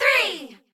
threegirls.ogg